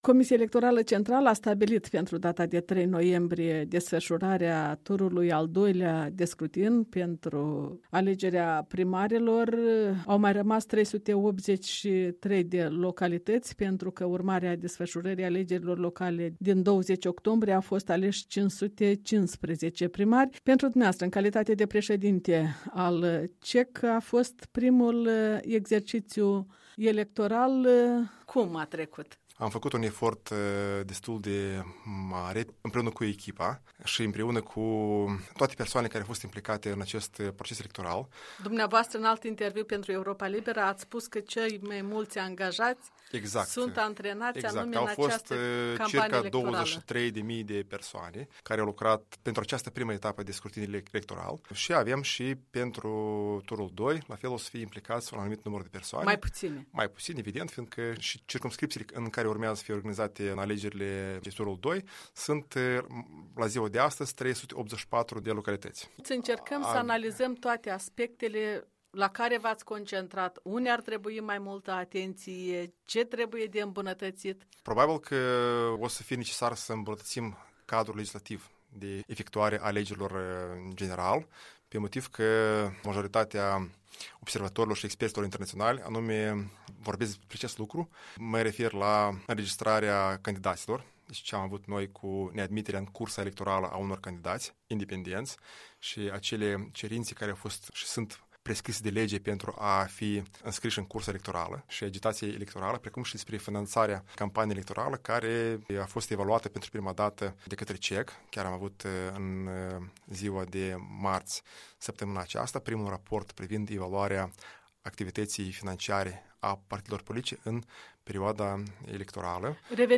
Interviu cu președintele Comisiei Electorale Centrale.